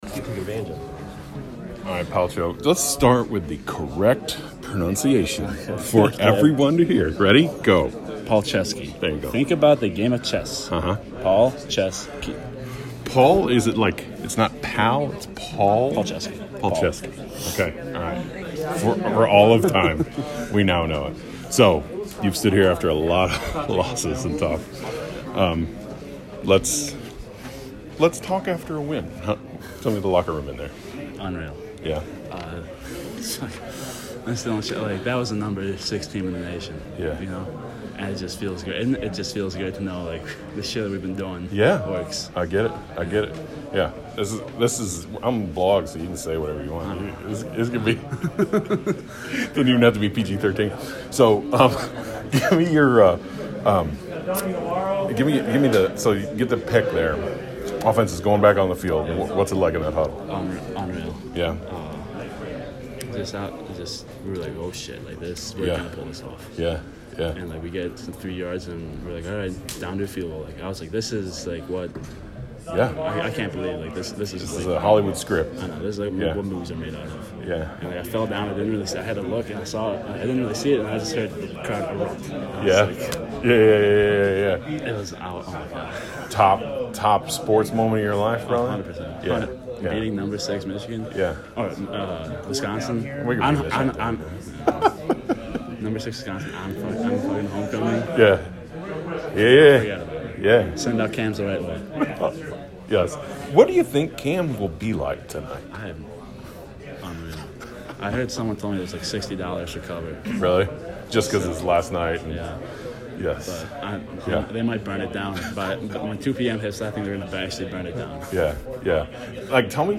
Wisconsin - Postgame Player Interviews